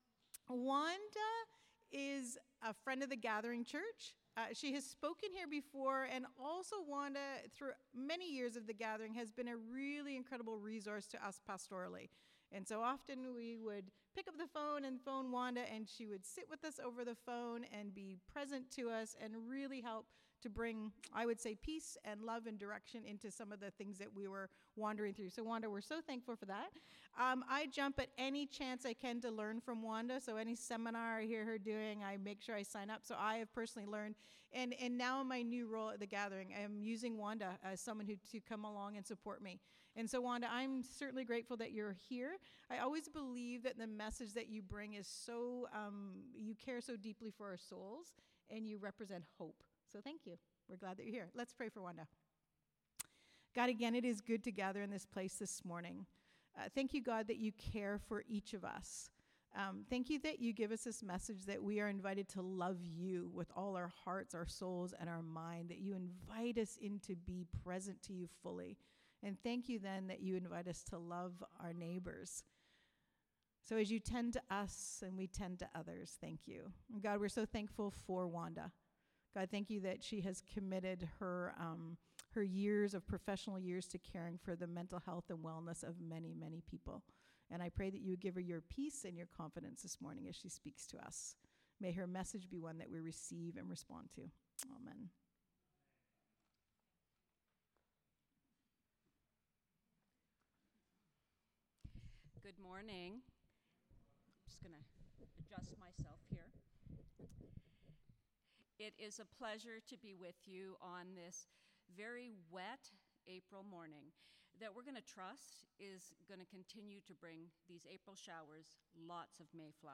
Sermons | The Gathering Church